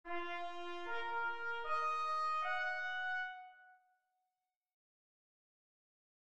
The second is diatonic (the notes exist in a number of both major and minor keys) and can easily be distinguished by its opening rising intervals of a fourth: